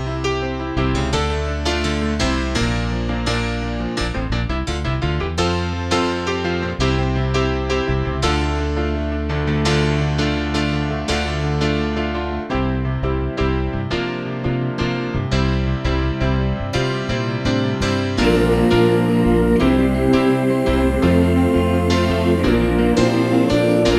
Two Semitones Down Rock 8:06 Buy £1.50